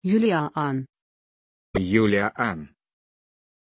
Aussprache von Juliaan
pronunciation-juliaan-ru.mp3